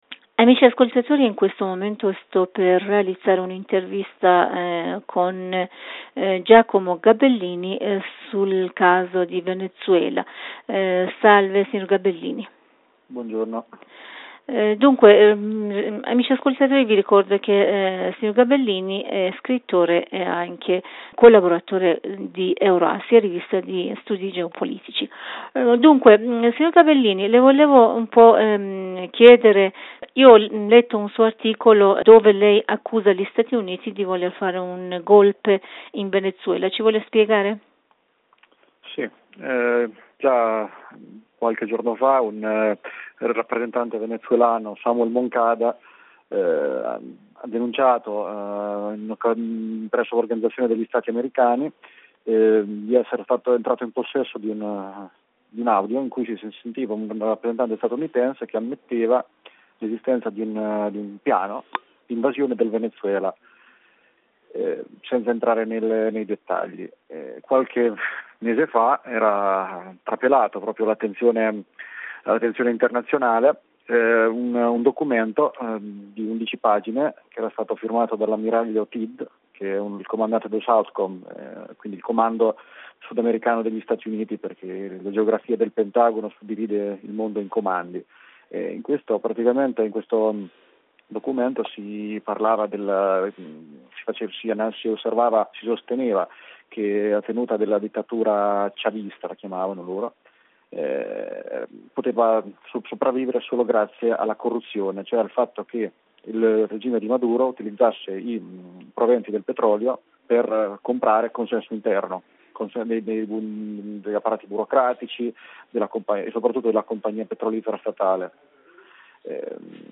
Notiziario / mondo